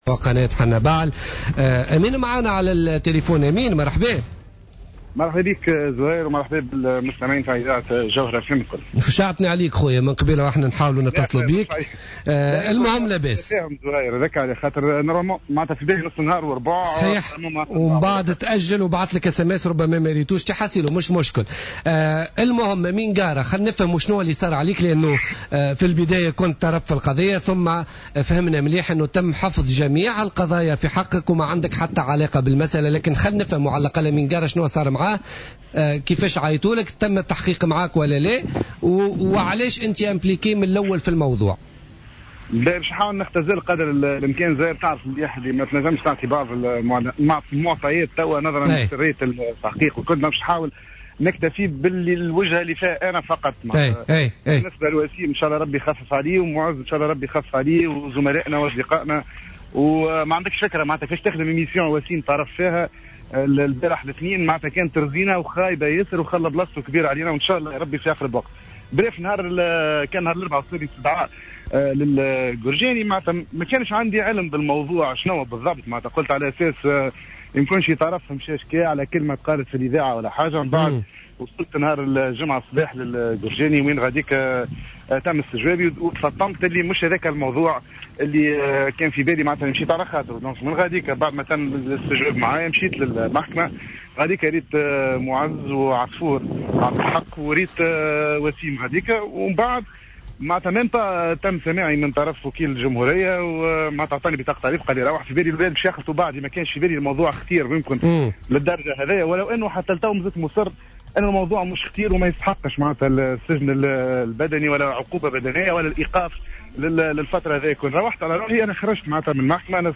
أكد الإعلامي أمين قارة في مداخلة له في برنامج بوليتيكا اليوم الثلاثاء 17 مارس 2015 أنه تلقى استدعاء للتحقيق بفرقة الأبحاث بالقرجاني ولم يكن يعلم سبب استدعائه إلا حين مثوله أمام التحقيق مؤكدا تحفظه على الإدلاء بتفاصيل التحقيق .